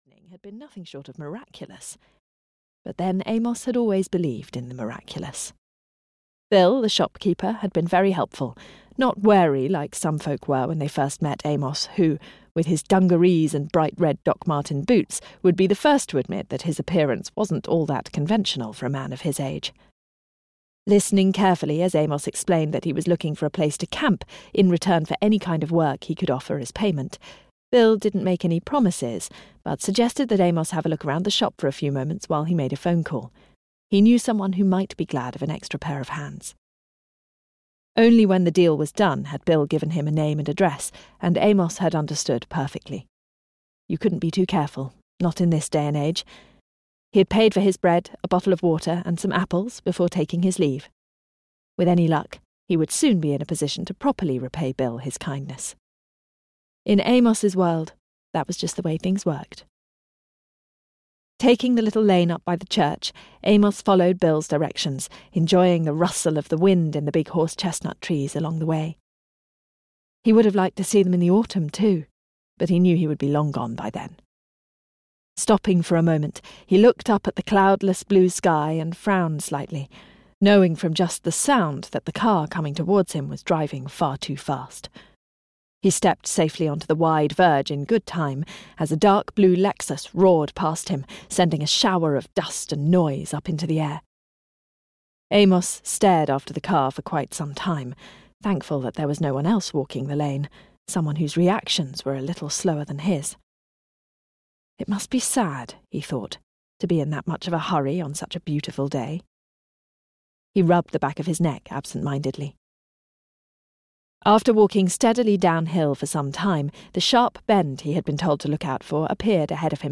The Beekeeper's Cottage (EN) audiokniha
Ukázka z knihy